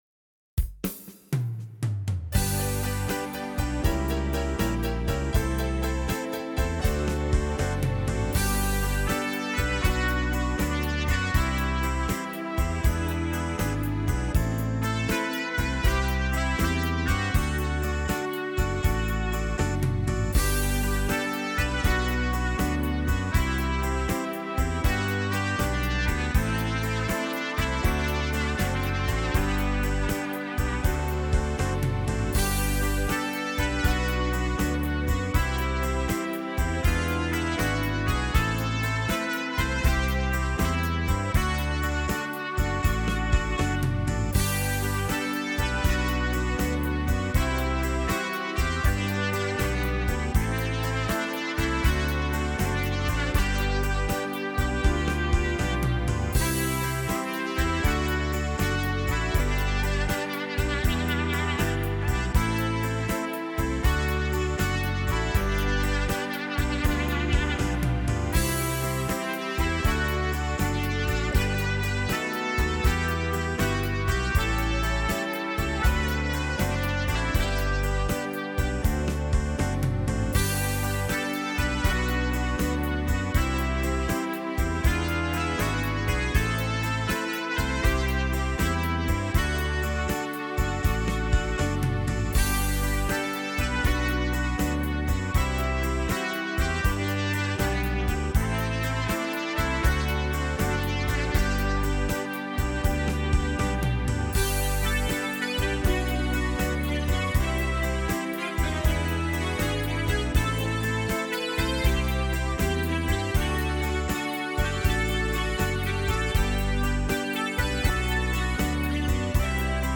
Slow Rock